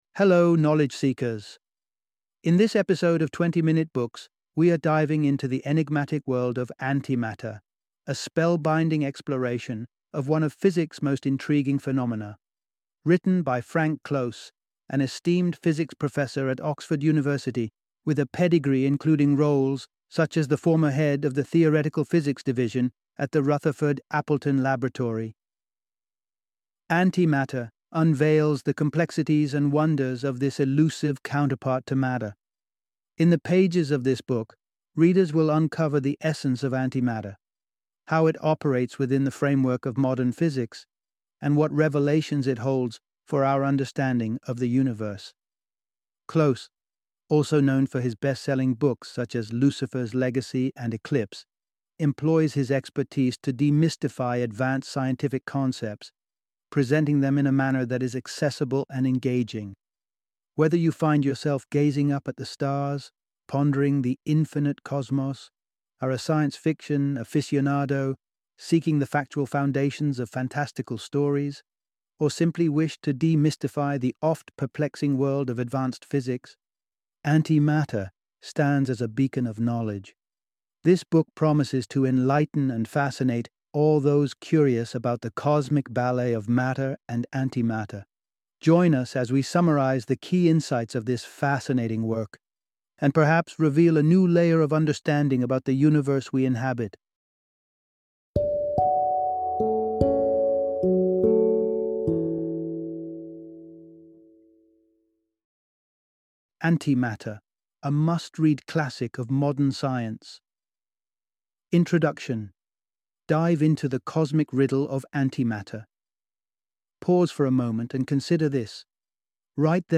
Antimatter - Audiobook Summary